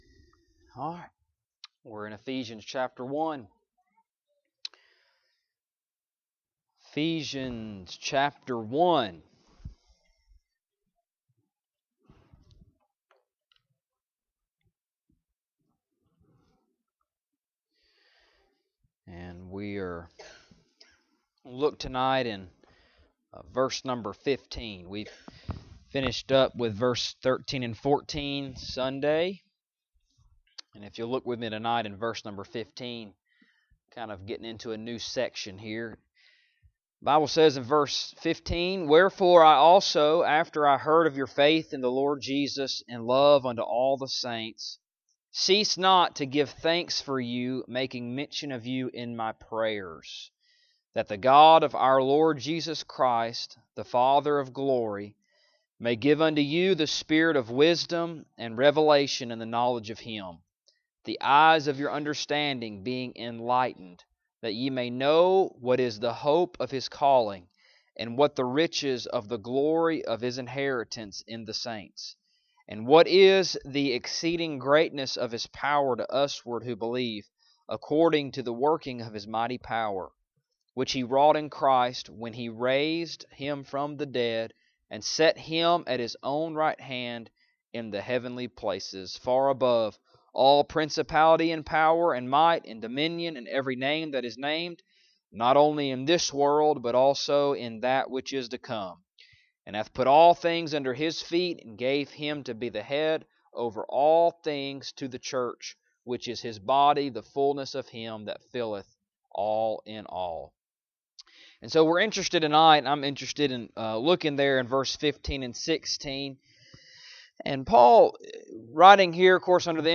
Ephesians Passage: Ephesians 1:15-23 Service Type: Wednesday Evening Topics